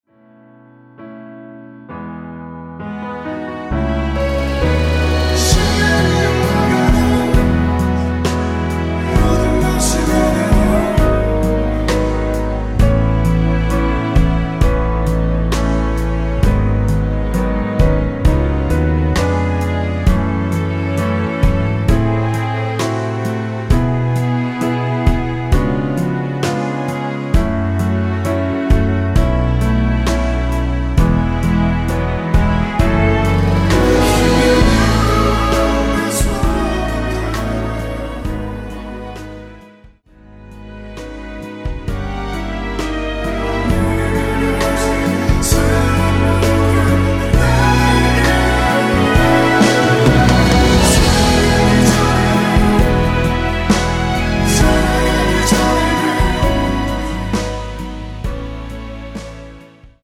원키에서(-1)내린 코러스 포함된 MR 입니다.(미리듣기 참조)
◈ 곡명 옆 (-1)은 반음 내림, (+1)은 반음 올림 입니다.
앞부분30초, 뒷부분30초씩 편집해서 올려 드리고 있습니다.